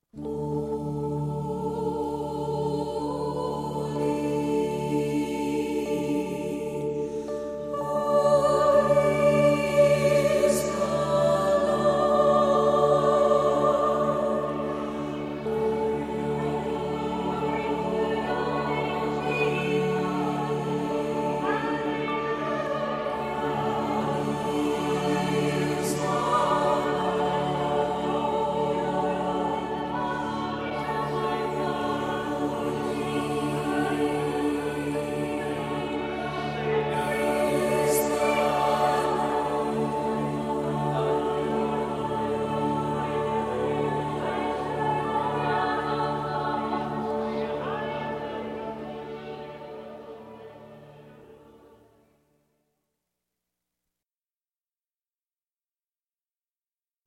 professionally recorded in 1982
Piano
Electric & acoustic guitars
Bass guitar
Percussion
Trumpet
English horn, saxophone and clarinet
Synthesizer